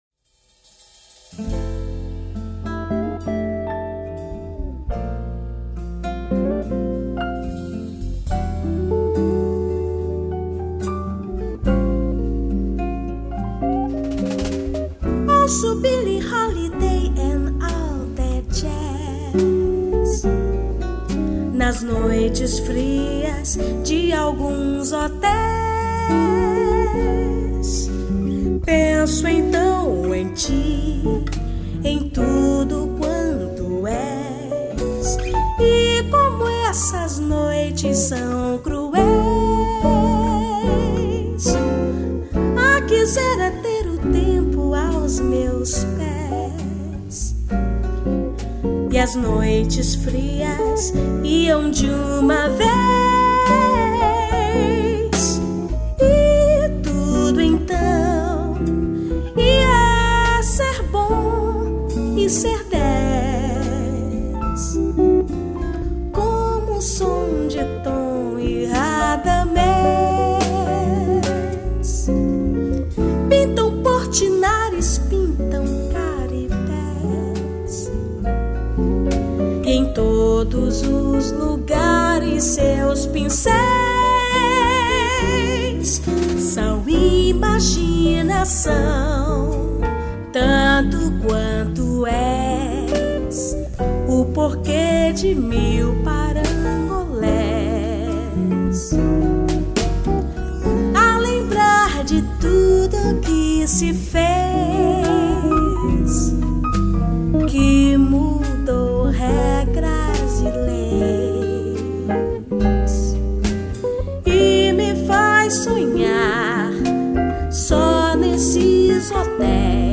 235   03:54:00   Faixa:     Jazz